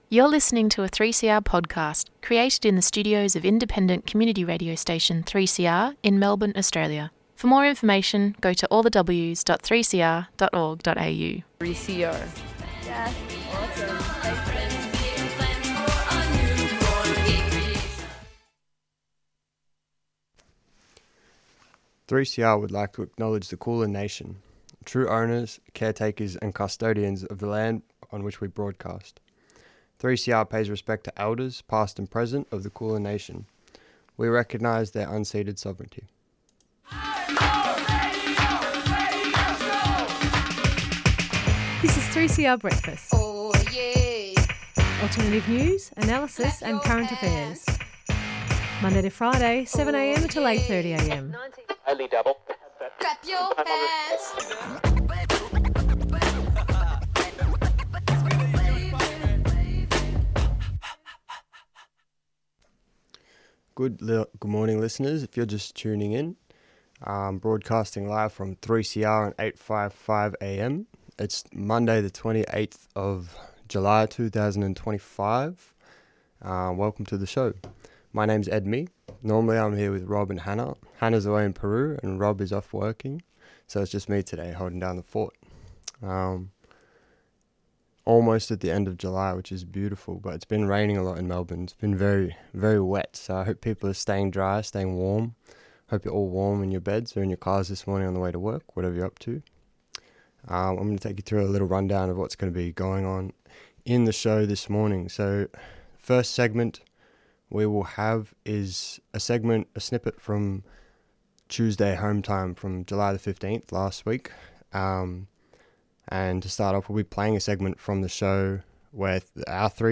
On today's show: Headlines: French parliament recognises 'New Caledonia' as its own state despite Indigenous Kanak resistance.